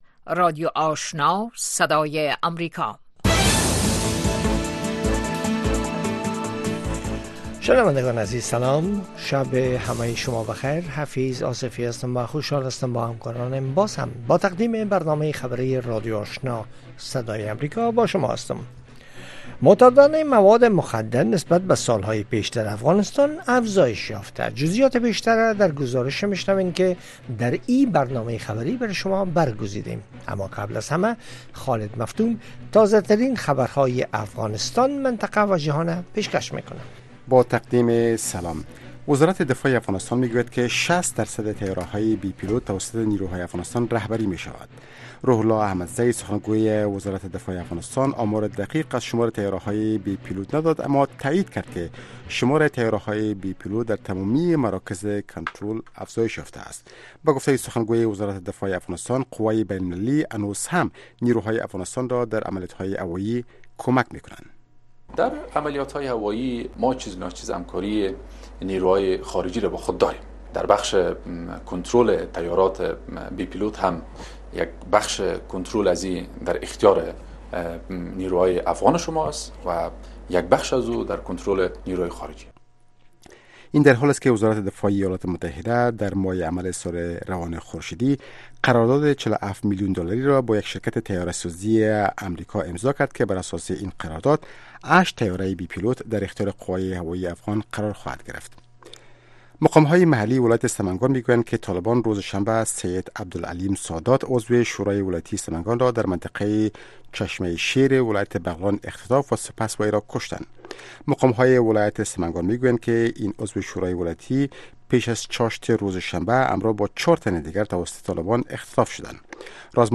دومین برنامه خبری شب